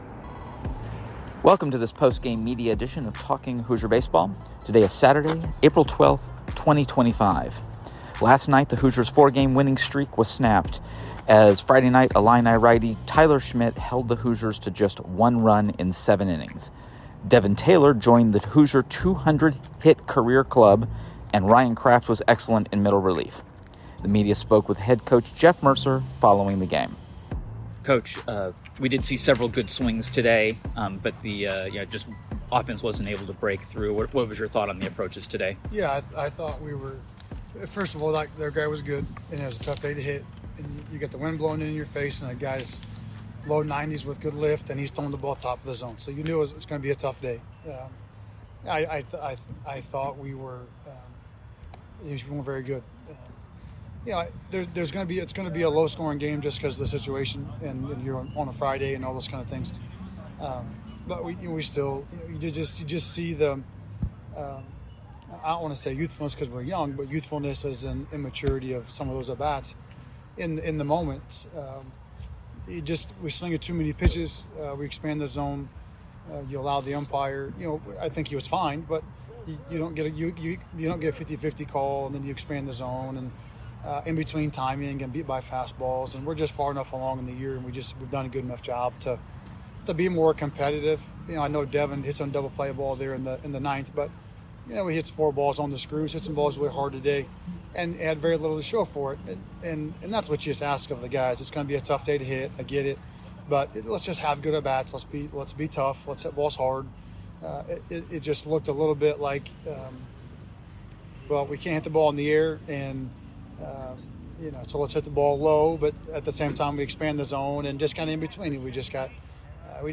Postgame Media at Illinois Friday